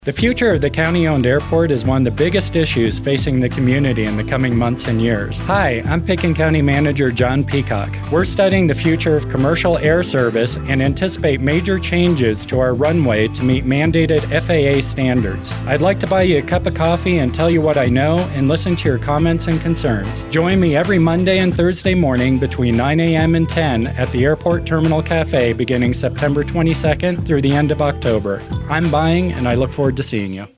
County Manager Jon Peacock on KSPN Radio